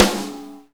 normal-hitclap.wav